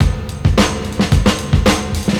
• 108 Bpm Drum Beat D# Key.wav
Free drum beat - kick tuned to the D# note. Loudest frequency: 1110Hz
108-bpm-drum-beat-d-sharp-key-5UG.wav